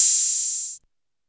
Open Hats